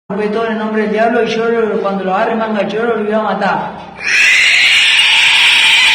Play, download and share man in jail screeches original sound button!!!!
man-in-jail-screeches.mp3